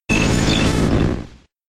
Cri de Tentacruel K.O. dans Pokémon X et Y.